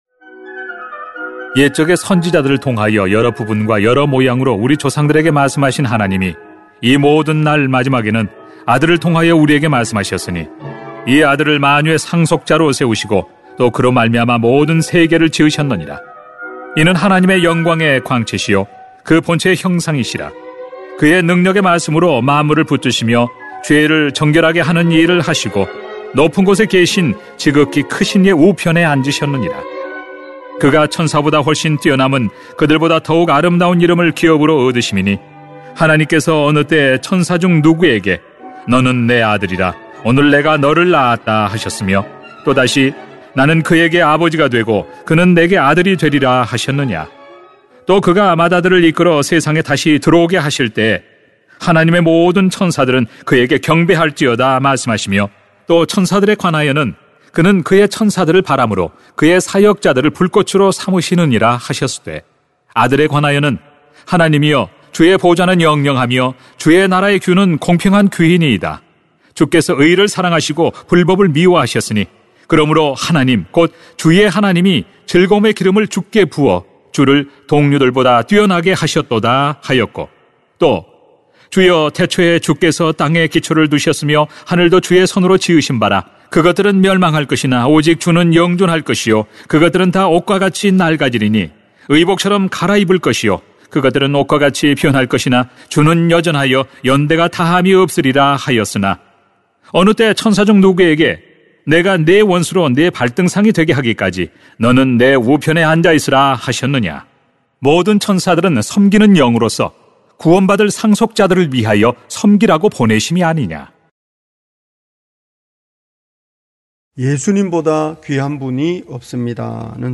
[히 1:1-14] 예수님보다 귀한분이 없습니다 > 새벽기도회 | 전주제자교회